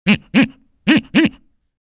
Funny Toy Duck Quacking – Comic Sound Effect
This funny toy duck quacking sound effect delivers a rhythmic, cartoon-style tone. It adds clear, playful quacks that bring humor and energy to any scene.
Funny-toy-duck-quacking-comic-sound-effect.mp3